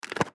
Звук застегивающегося шлема картингиста